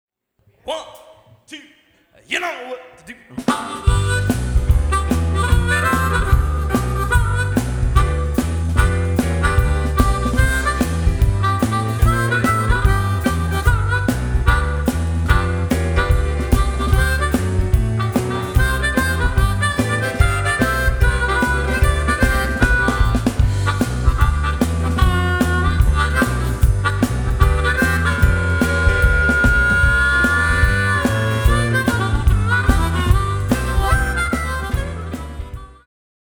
live performances
blues band
No remixing or overdubs!